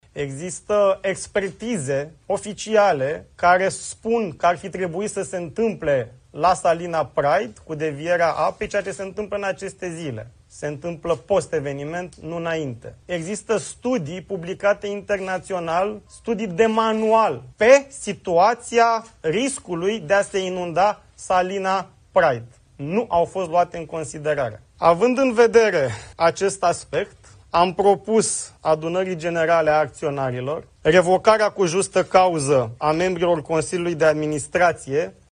Riscurile de inundare a Salinei Praid erau cunoscute încă din 2006, dar instituțiile statului și-au pasat răspunderea, a mai declarat, într-o conferință de presă, Radu Miruță.